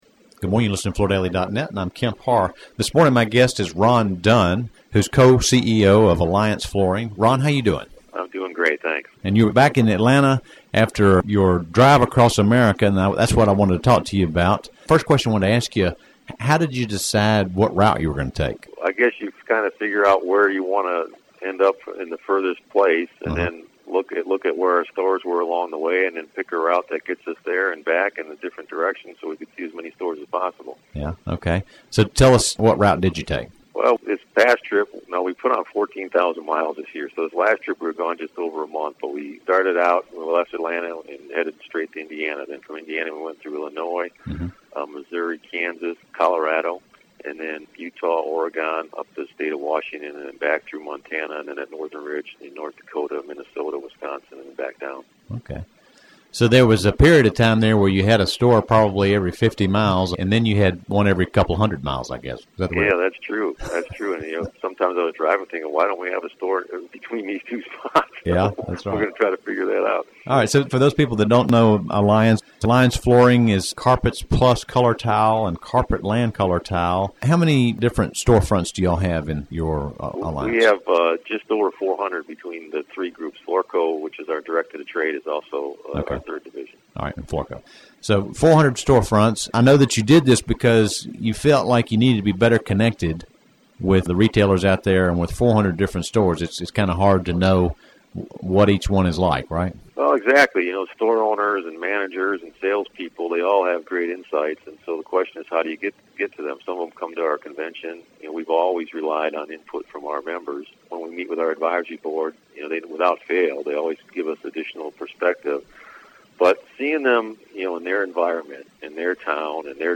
Listen to the interview to hear some great success tips on product diversity, focus on fashion (with vignettes and Ipad presentation tools).